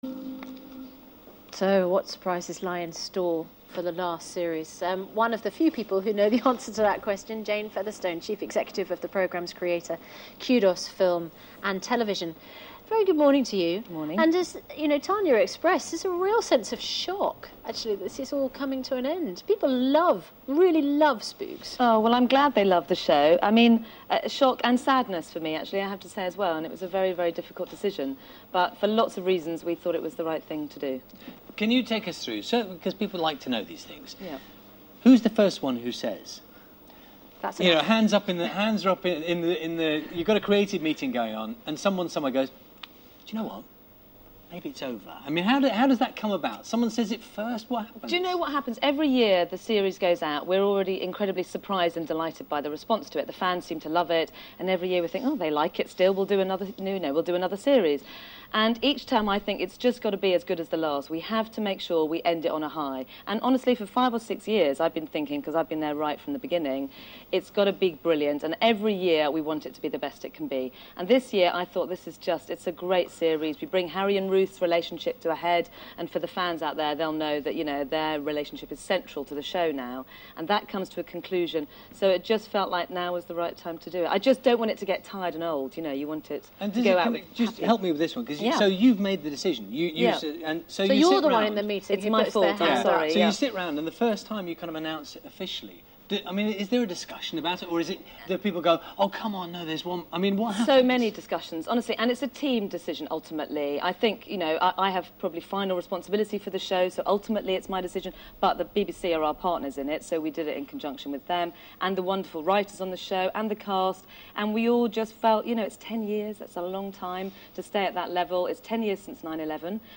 Three quotes from Jane Featherstone live on the BBC1 Breakfast sofa around 8:40am today.
The hosts asking the questions are Susanna Reid and Charlie Stayt: